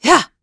Isolet-Vox_Attack6.wav